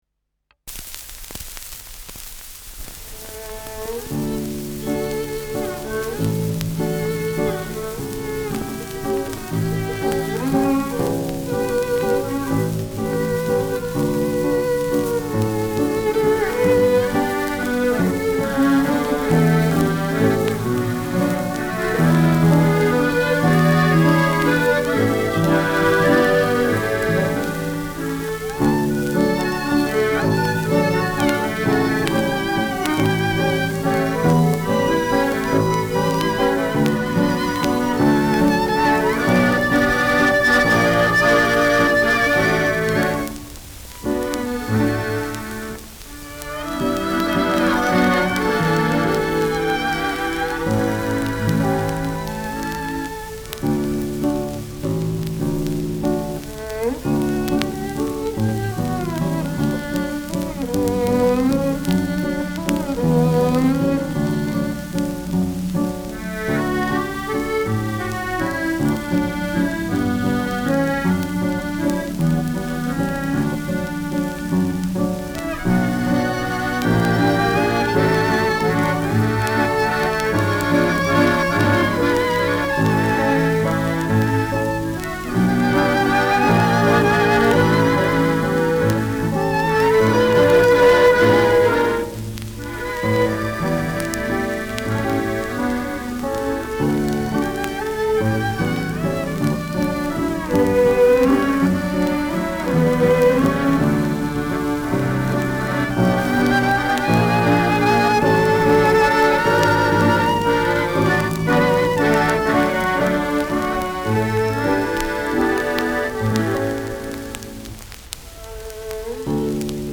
Schellackplatte
leichtes Rauschen : leichtes Knistern
[Berlin] (Aufnahmeort)